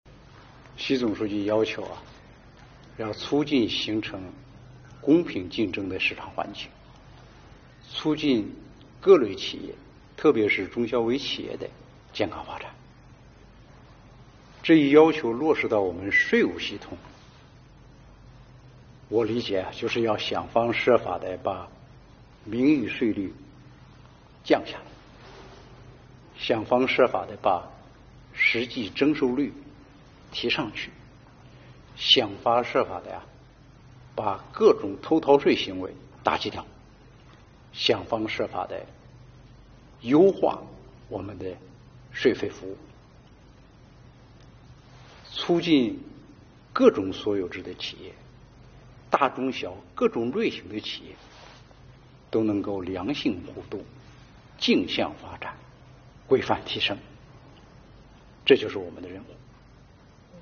经济日报记者就此采访了国家税务总局局长王军。